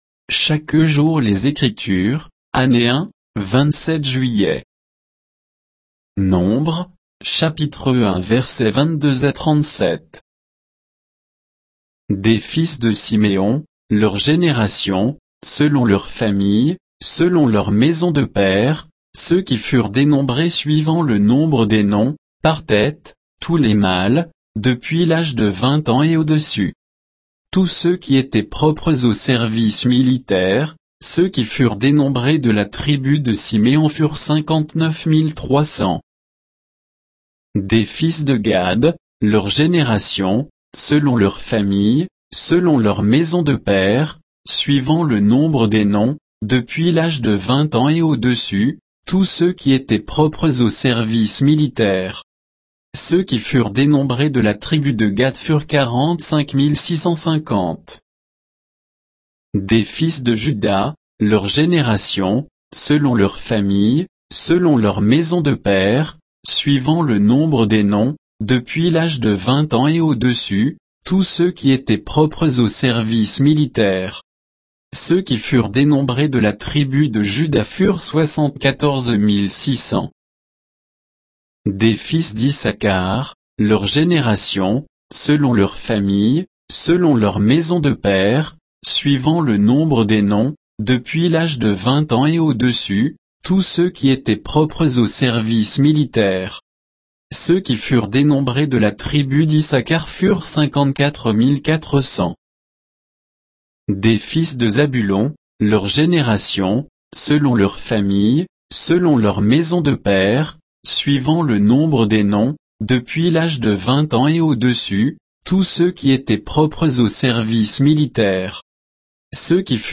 Méditation quoditienne de Chaque jour les Écritures sur Nombres 1, 22 à 37